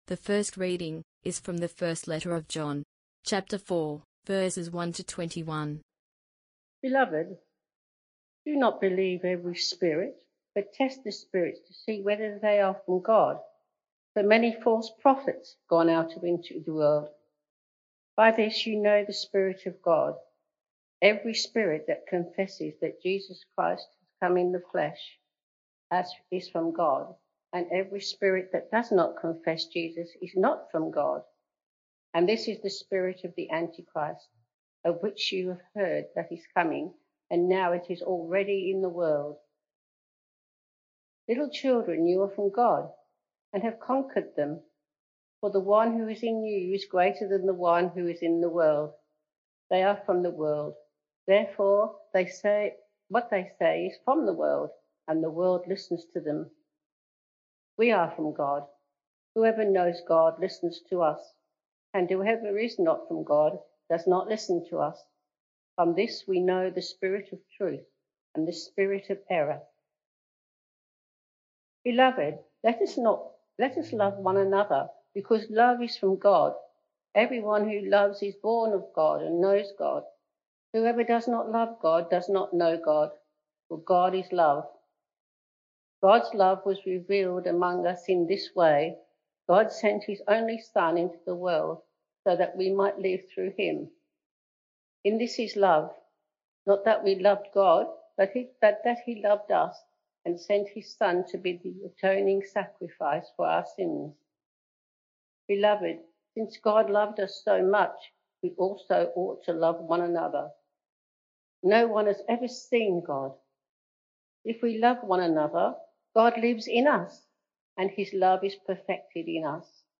Sermon-5th-May-2024.mp3